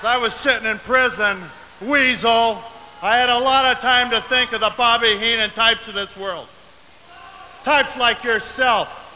Soon enough, Patera was able to get face to face with Heenan in the form of a verbal debate.
“I had a lot of time to think of the Bobby Heenan types of this world,” Patera continued.
bobbyheenantypes.wav